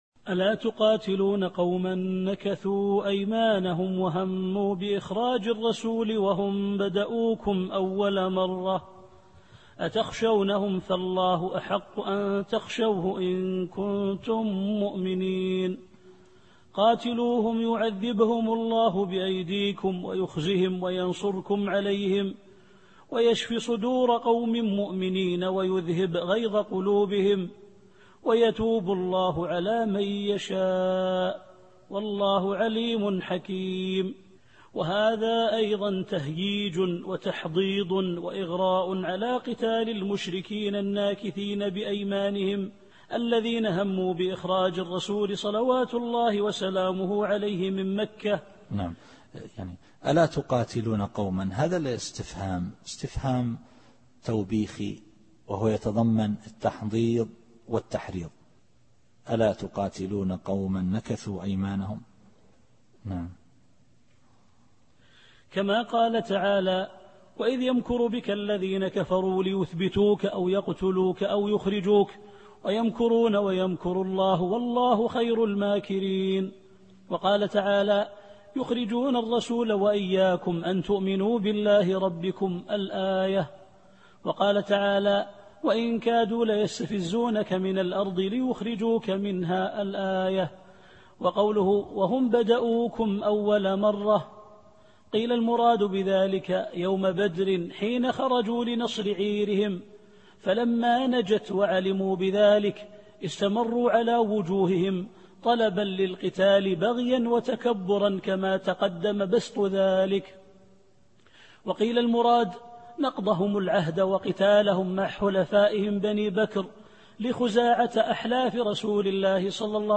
التفسير الصوتي [التوبة / 13]